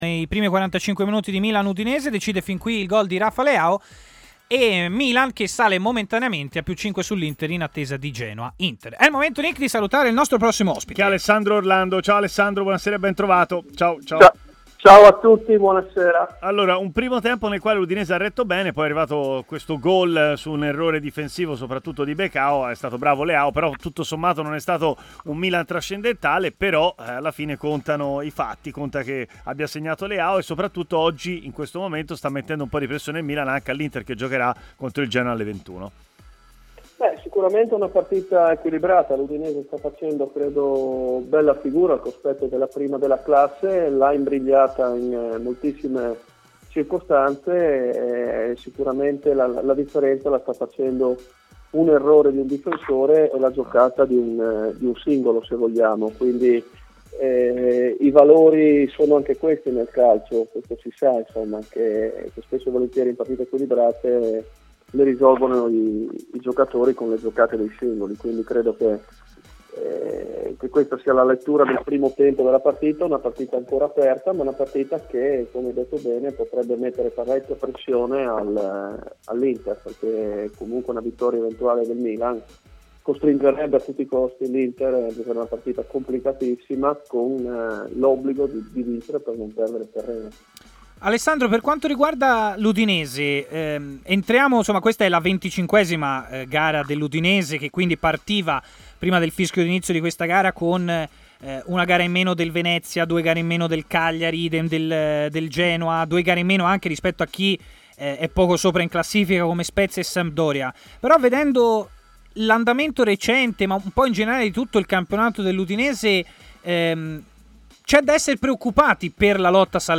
L'ex difensore Alessandro Orlando ha parlato in diretta durante Stadio Aperto, trasmissione di TMW Radio